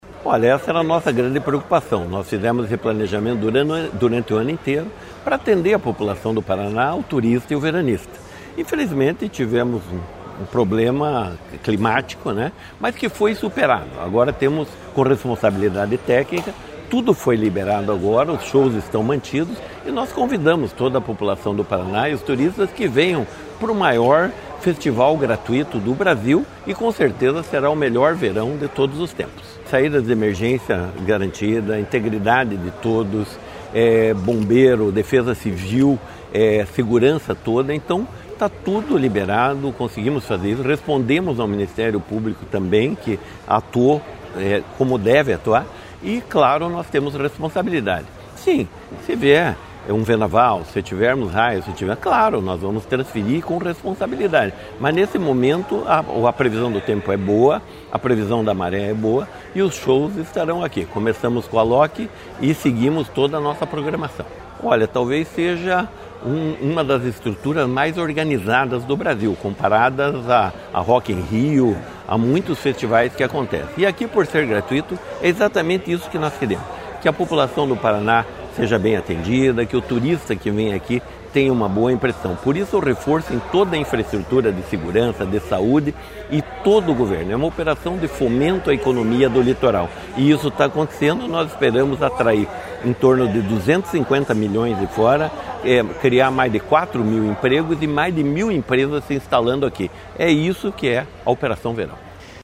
Sonora do secretário estadual do Esporte e coordenador do Verão Maior, Hélio Wirbiski, sobre a manutenção dos shows do Verão Maior em Caiobá